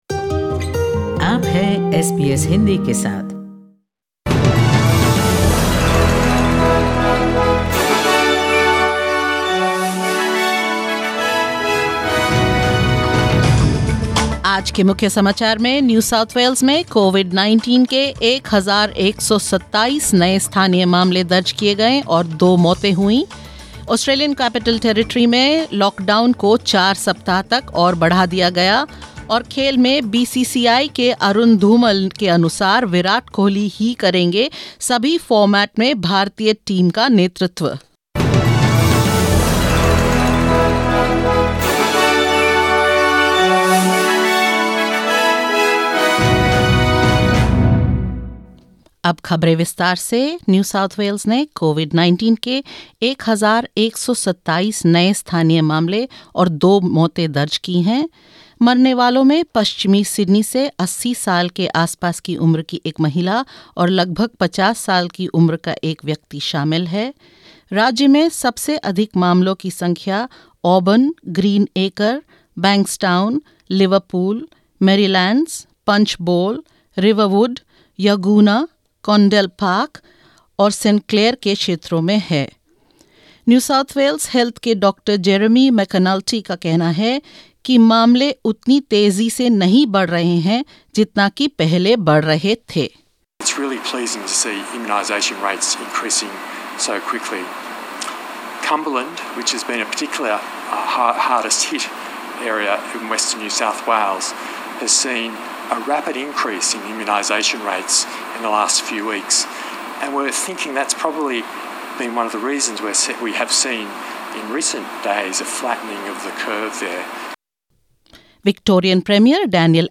In this latest SBS Hindi News bulletin of Australia and India: New South Wales records 1,127 new locally acquired COVID-19 cases and two deaths; Premier Daniel Andrews set to release Victoria’s roadmap out of lockdown on Sunday; BCCI clarifies that Virat Kohli will remain Team India’s captain in all formats and more.